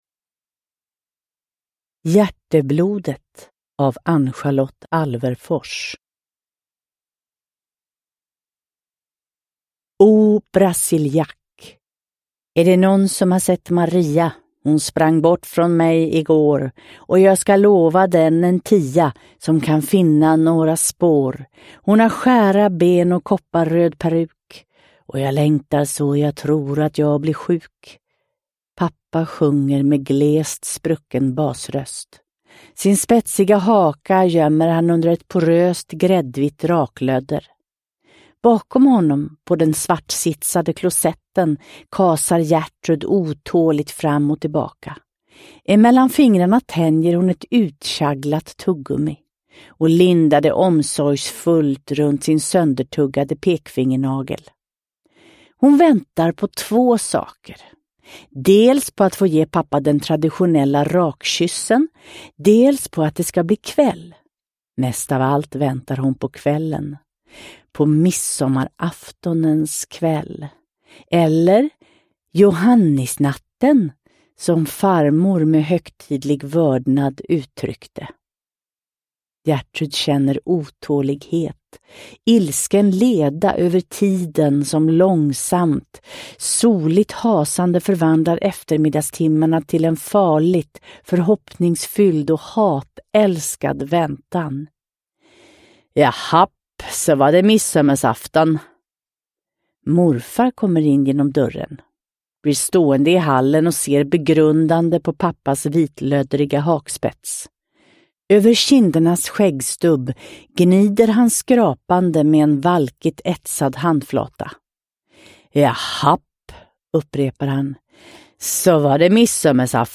Hjärteblodet – Ljudbok – Laddas ner
Uppläsare: Cecilia Nilsson